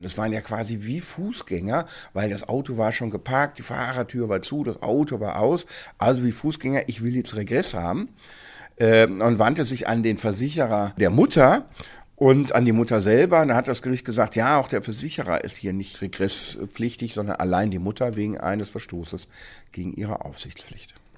O-Ton: Unfall eines Kindes beim Überqueren der Straße nach dem Aussteigen – Vorabs Medienproduktion